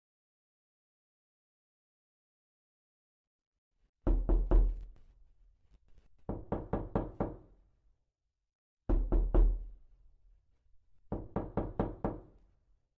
door knocking sound effect no sound effects free download